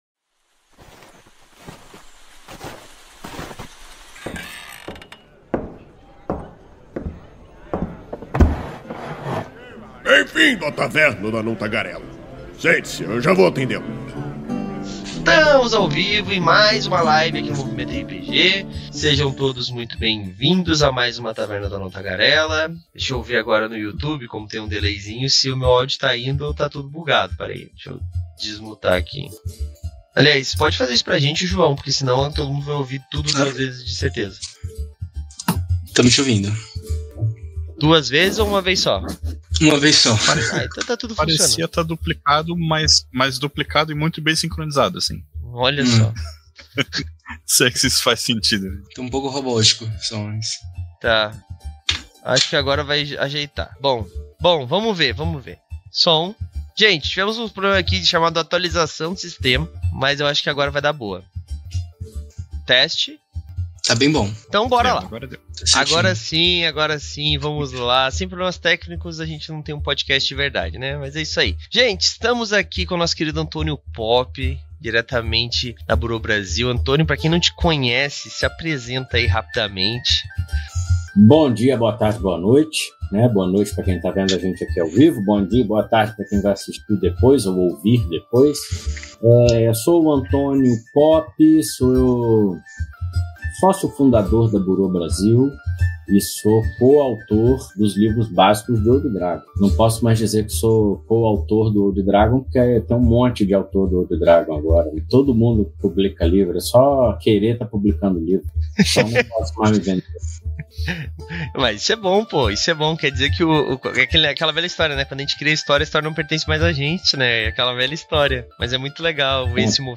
Venha conheça mais sobre a proposta desse guia, entenda como ele permite novas criações criativas de personagens nesse sistema e saiba como criar sua própria raça equilibrada com o sistema. A Taverna do Anão Tagarela é uma iniciativa do site Movimento RPG, que vai ao ar ao vivo na Twitch toda a segunda-feira e posteriormente é convertida em Podcast. Com isso, pedimos que todos, inclusive vocês ouvintes, participem e nos mandem suas sugestões de temas para que por fim levemos ao ar em forma de debate.